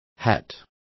Complete with pronunciation of the translation of hat.